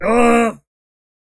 argg.ogg